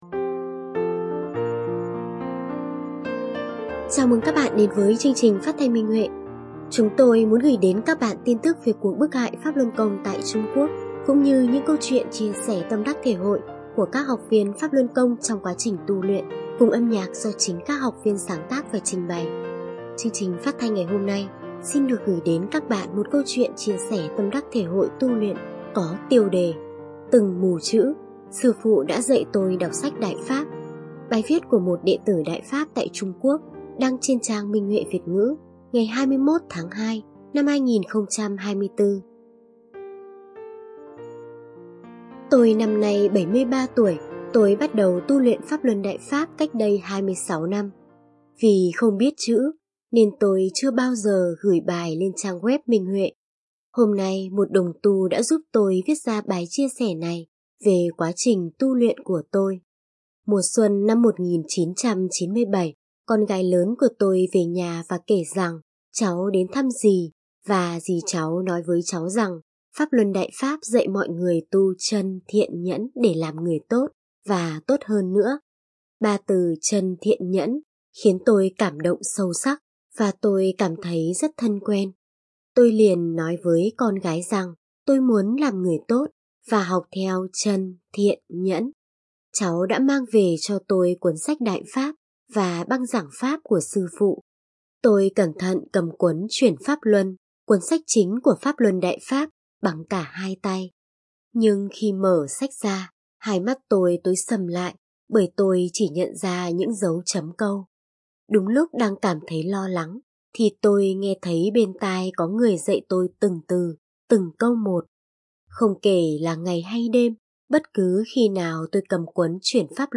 Phát thanh Minh Huệ (Câu chuyện tu luyện): Từng mù chữ, Sư phụ đã dạy tôi đọc sách Đại Pháp